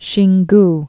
(shēng-g)